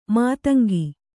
♪ mātangi